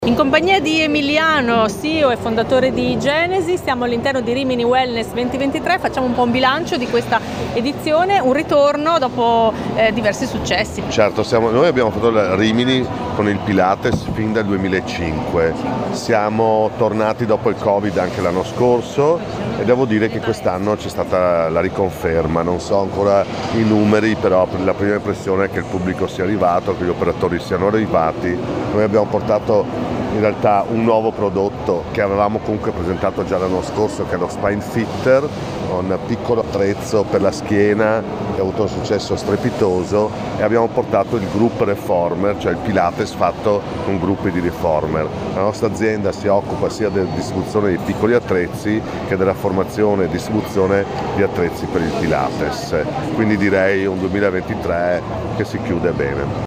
RIMINI WELNESS - Radio International Live